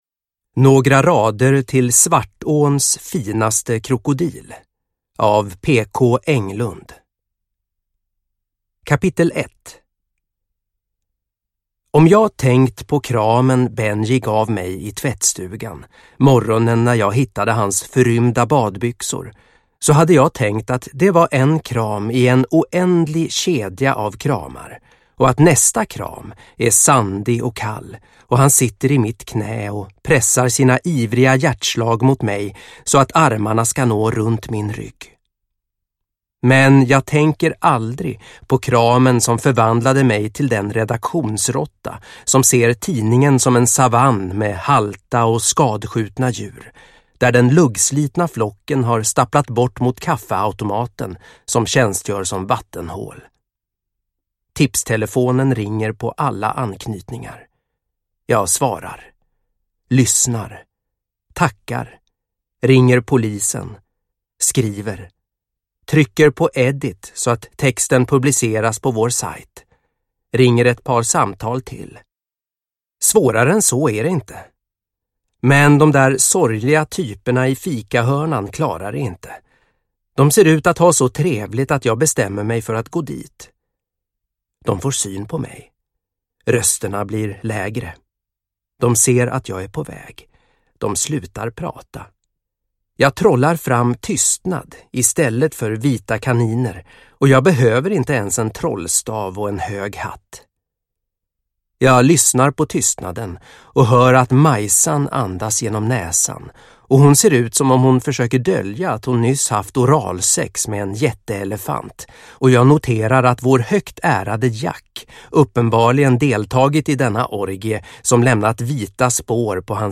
Några rader till Svartåns finaste krokodil – Ljudbok